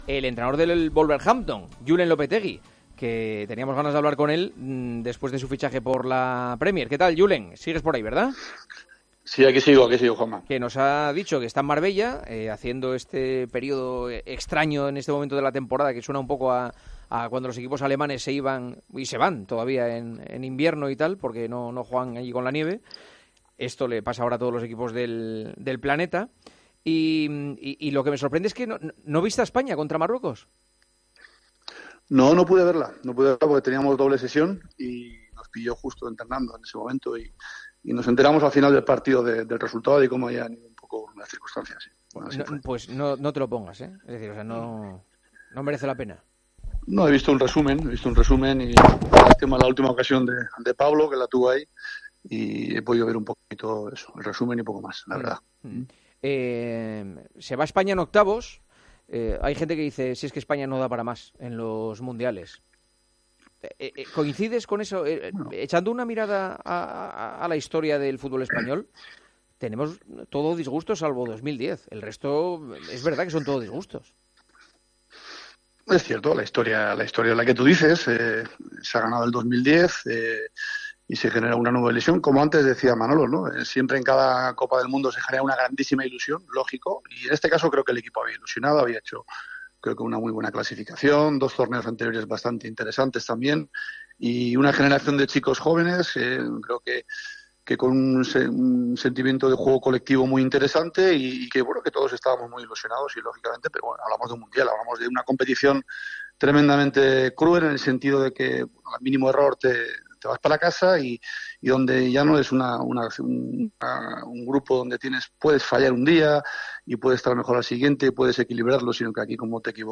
Dada la incierta situación que se vive en la RFEF con el futuro de Luis Enrique, El Partidazo de COPE llamó este miércoles a Julen Lopetegui, actual entrenador del Wolverhampton, que también sabe lo que es llevar las riendas de la Selección Española para pedirle opinión en torno a la decepción surgida por la eliminación a manos de Marruecos.